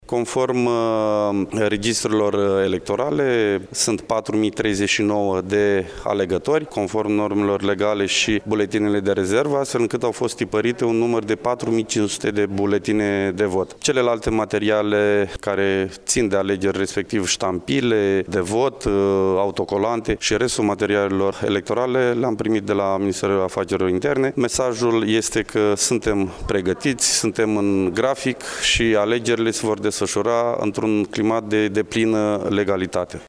Prefectul Marian Şerbescu: